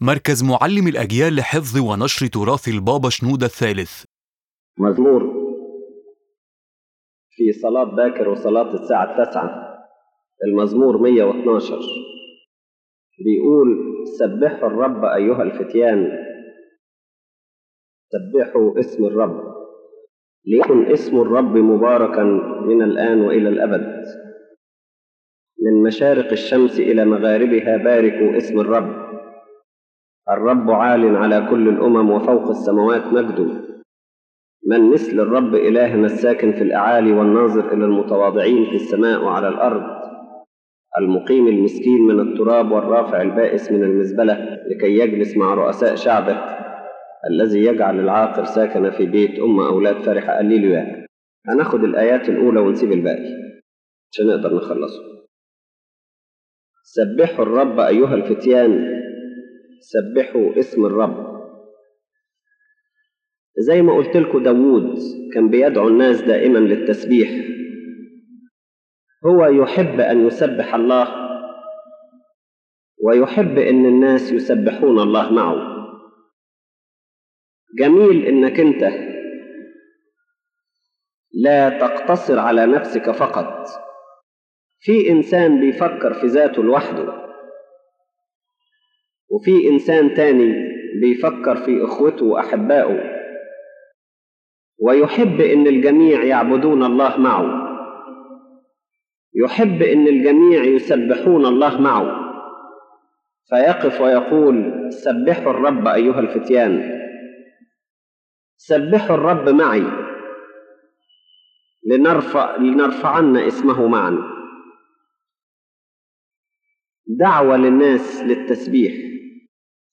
⬇ تحميل المحاضرة أولًا: دعوة عامة للتسبيح المزمور يدعونا إلى التسبيح لا كأمر فردي، بل كحياة جماعية: «سبحوا الرب أيها الفتيان».